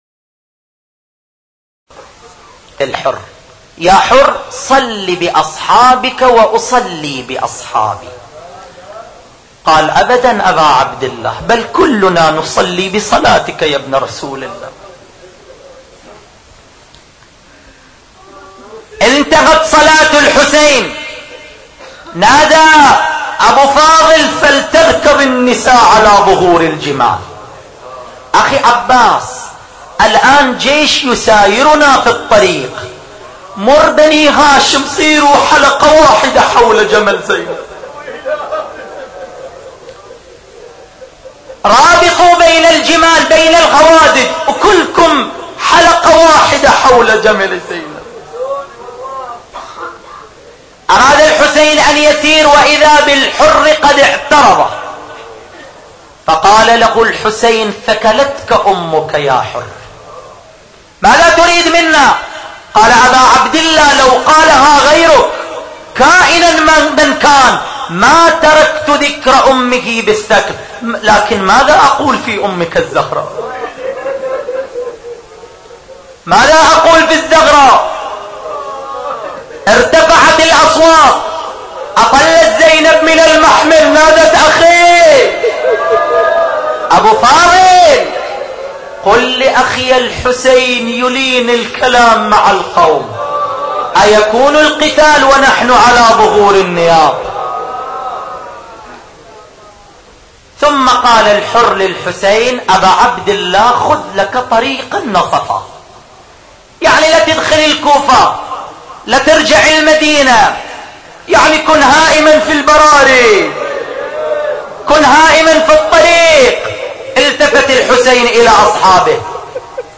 نواعي حسينية2